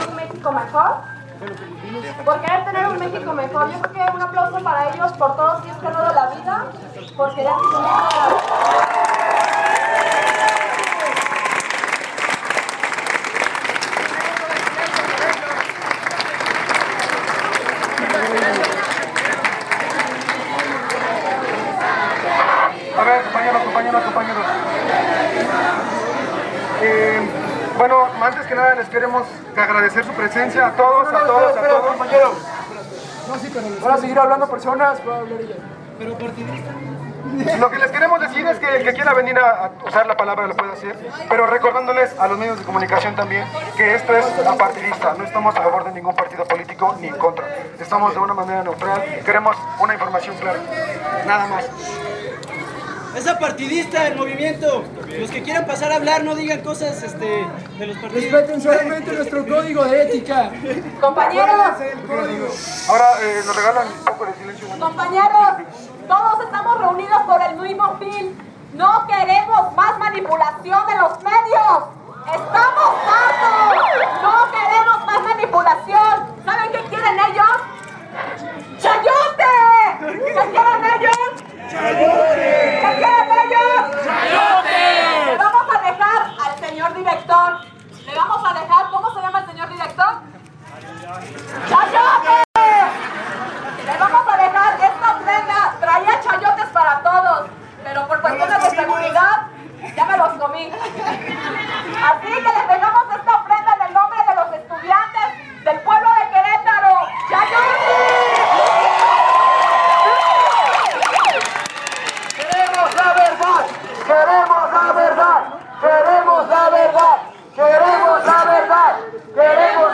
Discursos en la marcha 132